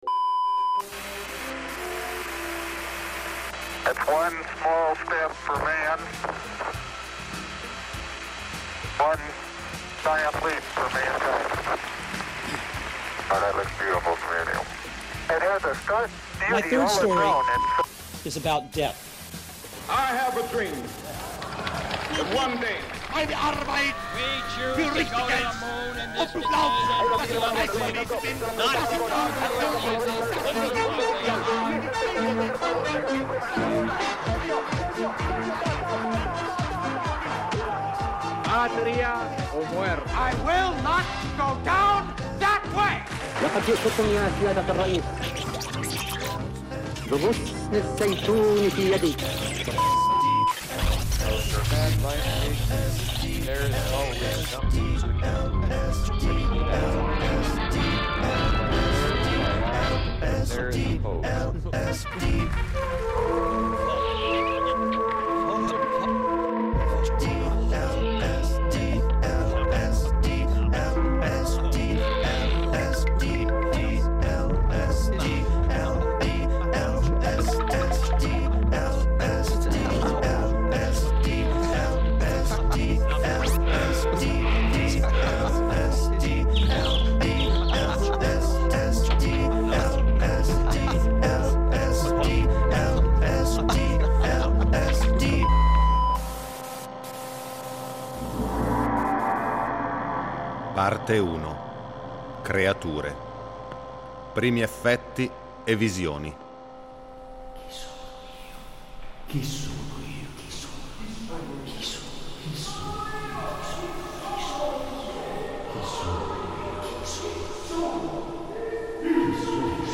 Dopo l’Apocalisse una radio trasmette i suoni di un mondo scomparso. Un lisergico viaggio sonoro per raccontare il viaggio dell’umanità.